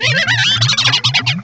pokeemerald / sound / direct_sound_samples / cries / toxicroak.aif
-Replaced the Gen. 1 to 3 cries with BW2 rips.